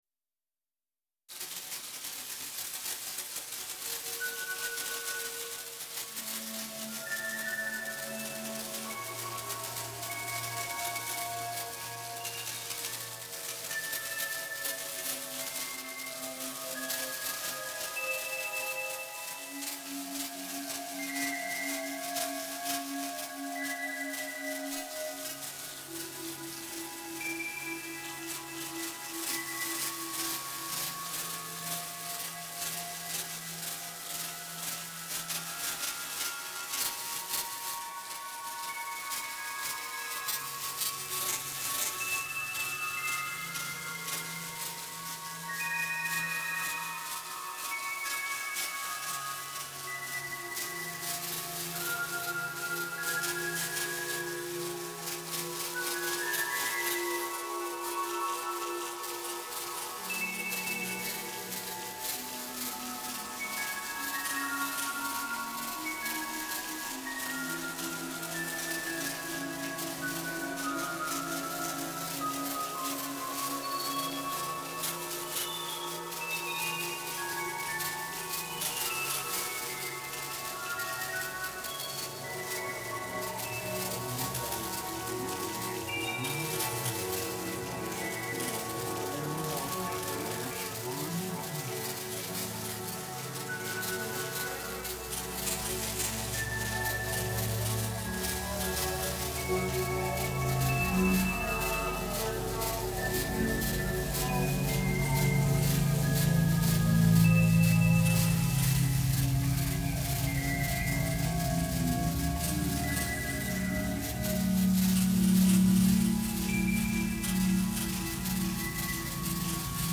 Rhythmical_Insects.02.mp3